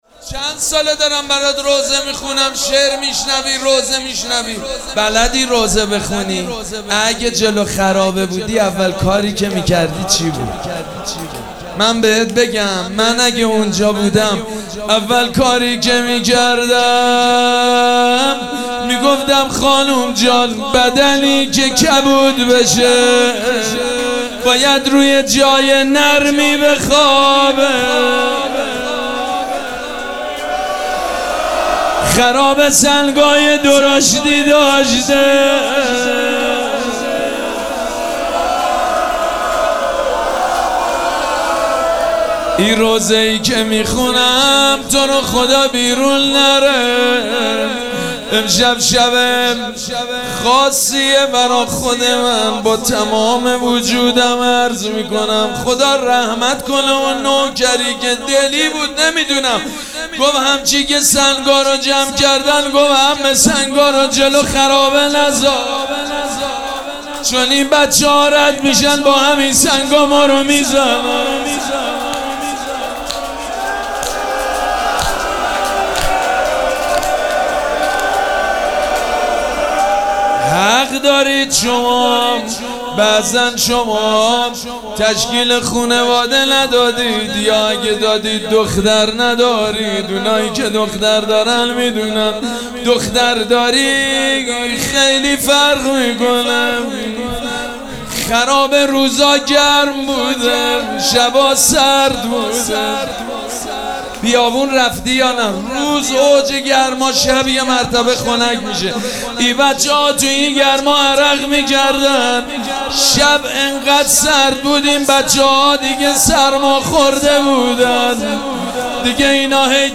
مراسم عزاداری شب شهادت حضرت رقیه سلام الله علیها
روضه